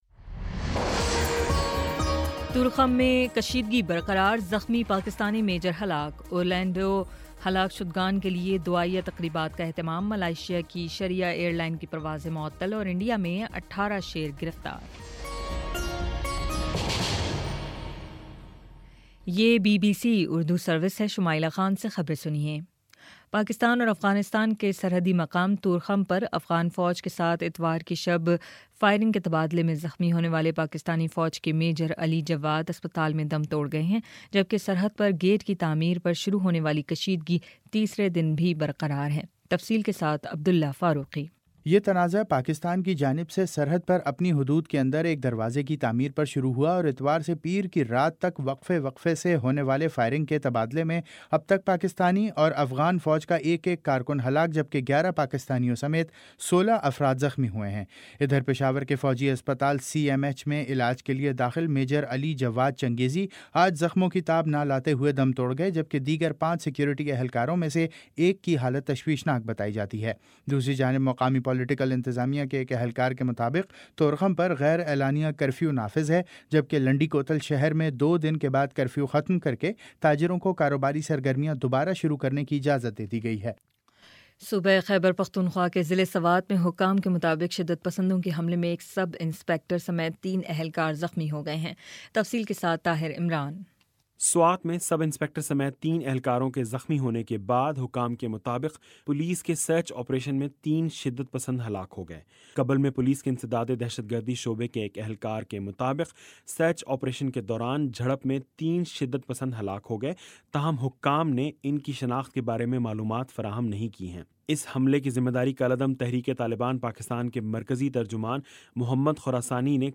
جون 14 : شام چھ بجے کا نیوز بُلیٹن